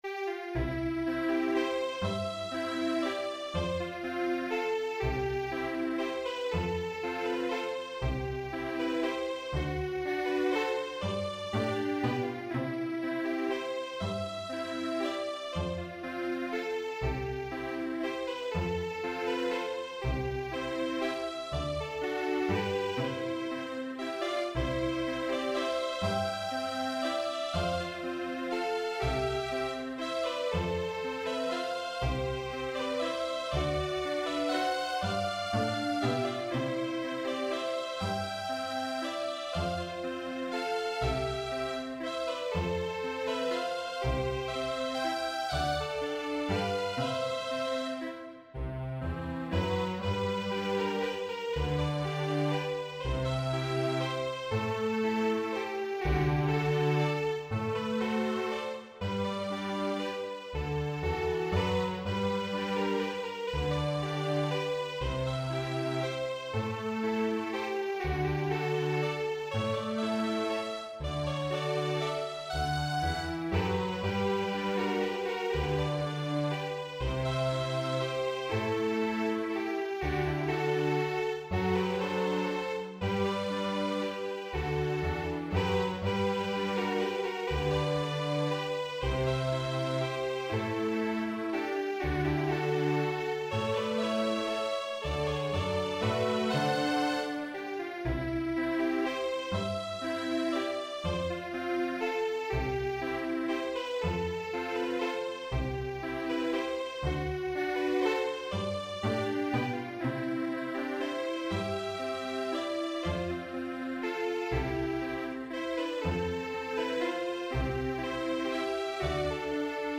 A contemporary Australian folk tune for String Ensemble